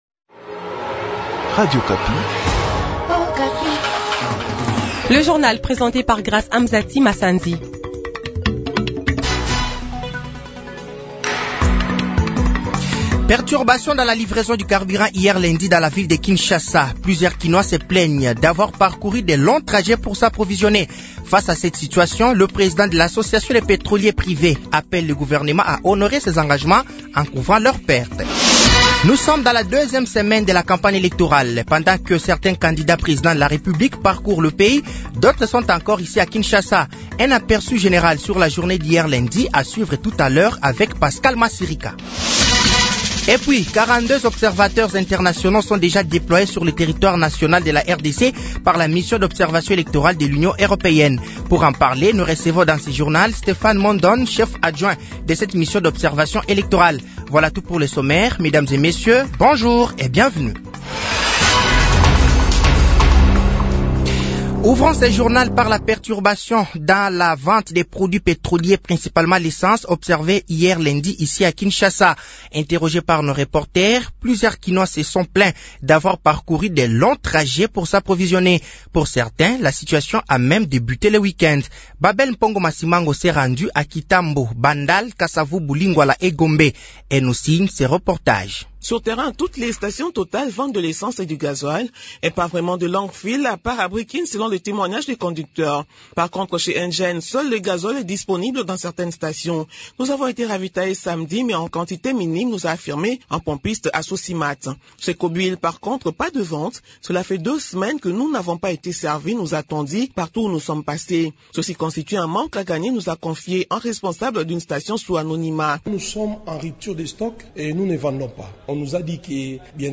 Journal matin
Journal français de 6h de ce mardi 28 novembre 2023